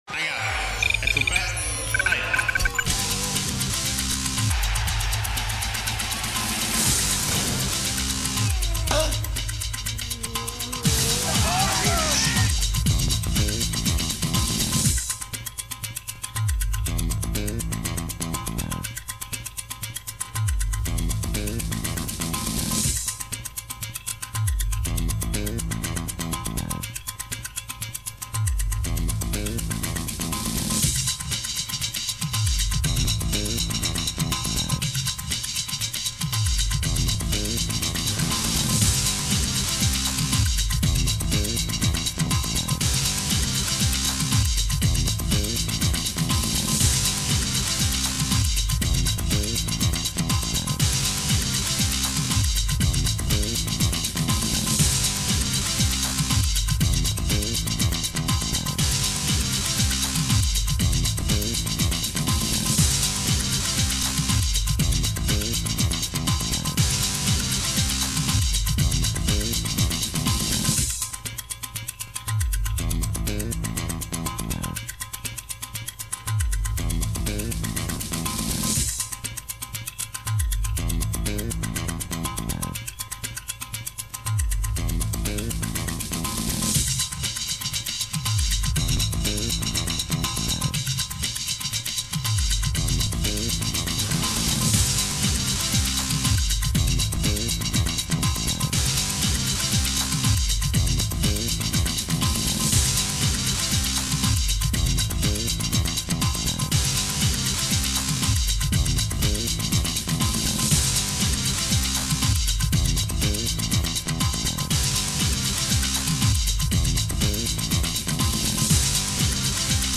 version longue du générique